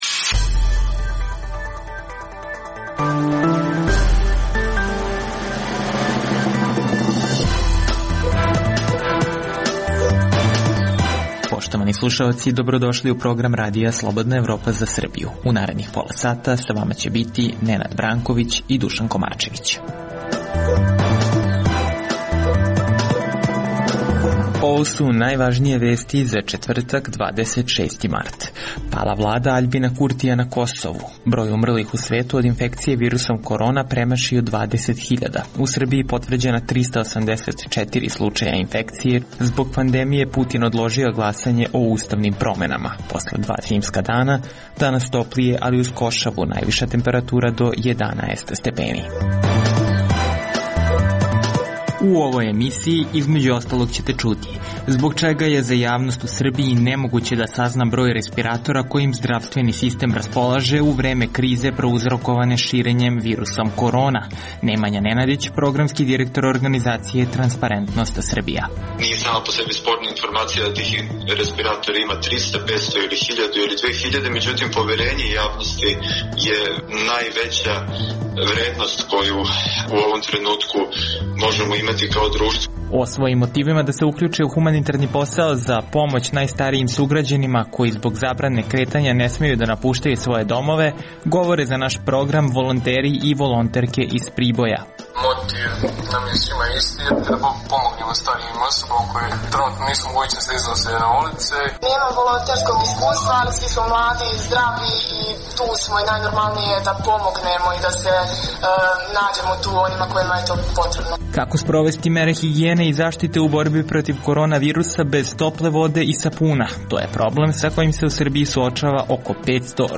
Osim najvažnijih vesti, poslušajte: Zbog čega je za javnost u Srbiji nemoguće da sazna broj respiratora kojim zdravstveni sistem raspolaže u vreme krize prouzrokovane širenjem virusom korona? O svojim motivima da se uključe u humanitarni posao za pomoć najstarijim sugrađanima koji zbog zabrane kretanja ne smeju da napuštaju, za naš program govore volonteri i volonterke iz Priboja. Nakon nepuna dva meseca od formiranja, u Skupštini Kosova sinoć je izglasano nepoverenje Vladi Aljbina Kurtija na zahtev njegovog koalicionog partnera - Demokratskog saveza Kosova.